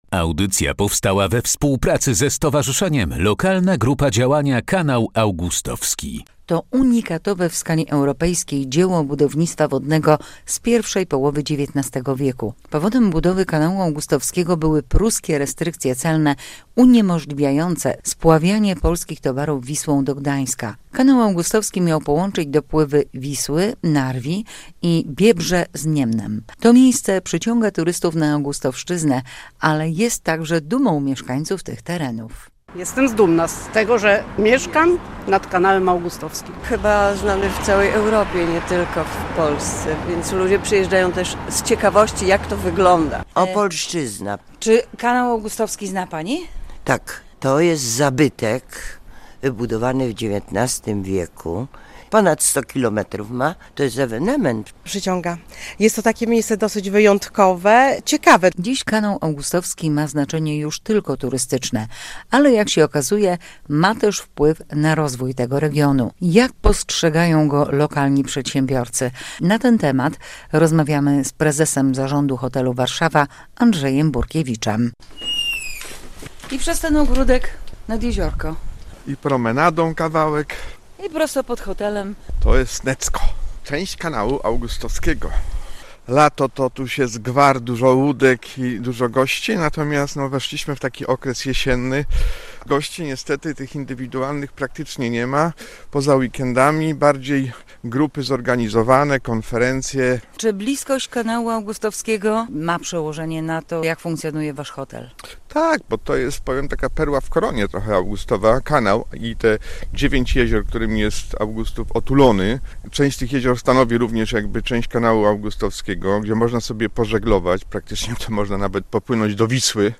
Odwiedzamy Augustów i opowiadamy o Kanale Augustowskim. Powodem budowy Kanału były pruskie restrykcje celne, uniemożliwiające spławianie polskich towarów Wisłą do Gdańska.